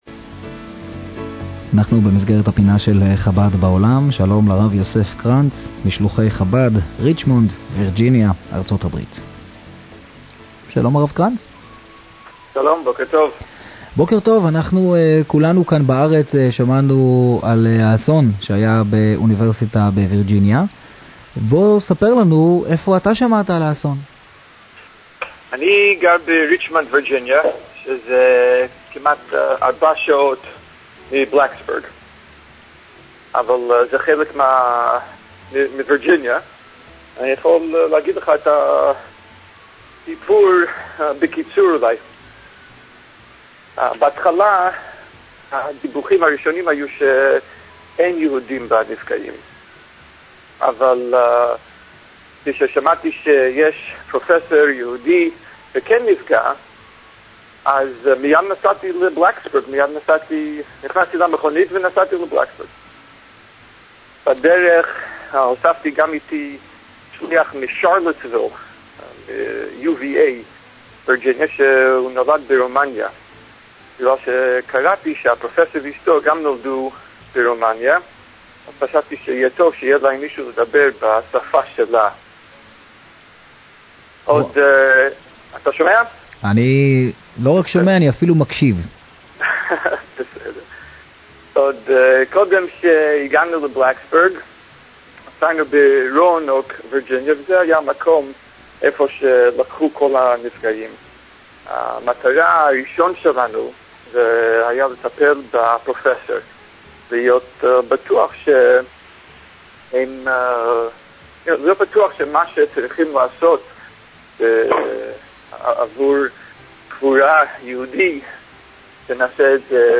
ארה"ב היה אורח הפינה הקבועה של ראיון עם שליח חב"ד
ברדיו "קול-חי".